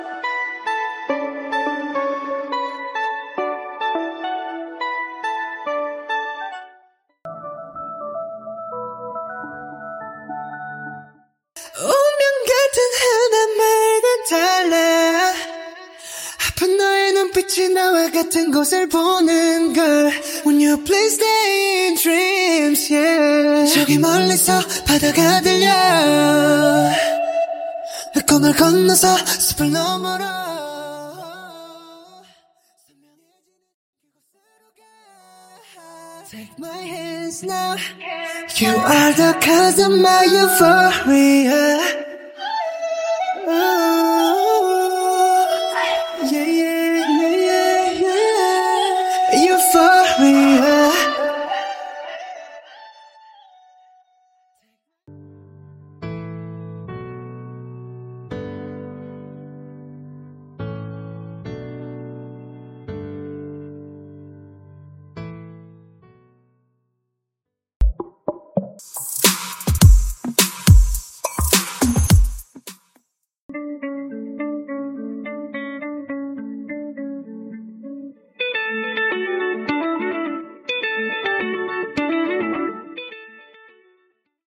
808 Bassline Stem
Backing Vox Stem
Guitar Strings Stem
Leading Vocals Stem
Percussion & Drums Stem
Piano Keys Stem
Synts & FX Stem